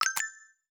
Special & Powerup (51).wav